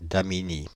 Damigny (French pronunciation: [damiɲi]